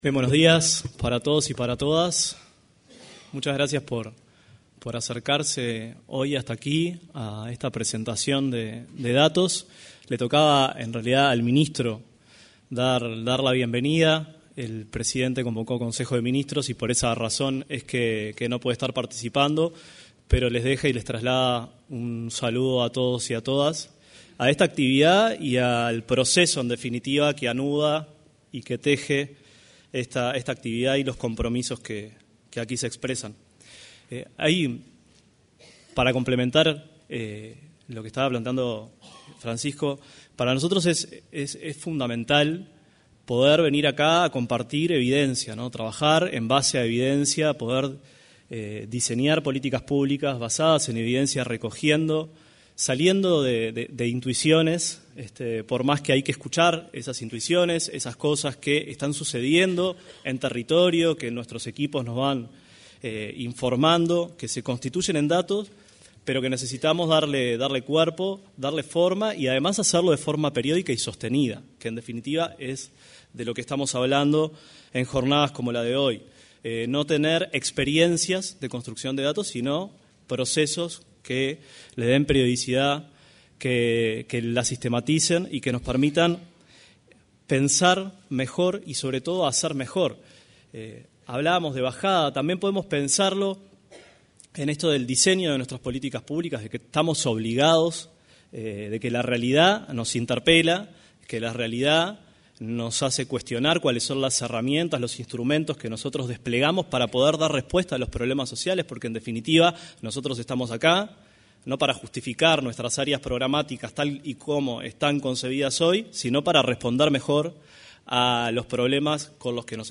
Se realizó la presentación de los resultados de la Encuesta Nacional sobre Violencia hacia Niños, Niñas y Adolescentes y la de Nutrición, Desarrollo Infantil y Salud realizada por el programa Uruguay Crece Contigo, del Ministerio de Desarrollo Social. En la oportunidad, se expresó el director nacional de Desarrollo Social, Nicolás Lasa.